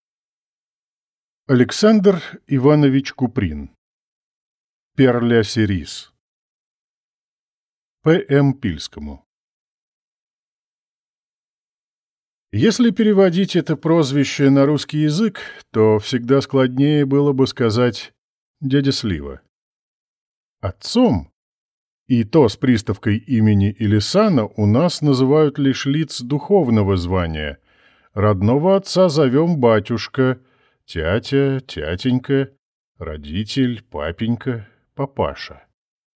Aудиокнига Пер-ля-Сериз